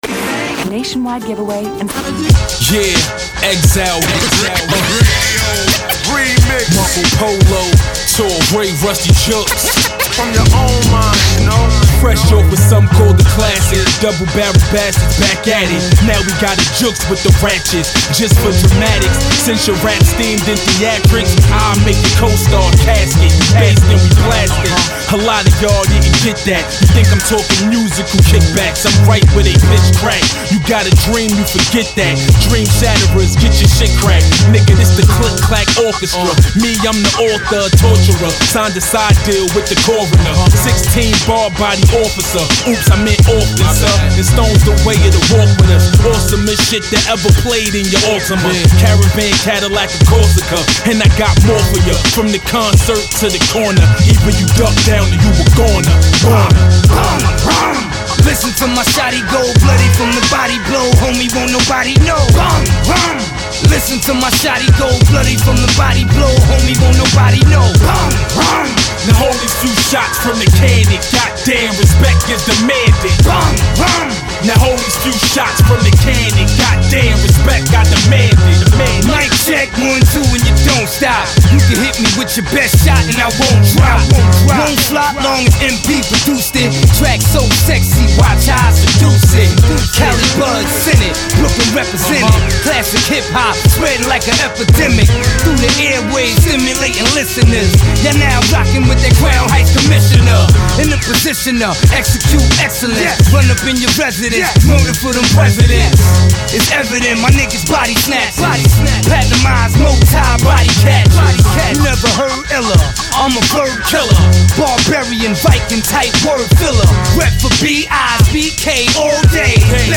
Underground Hip Hop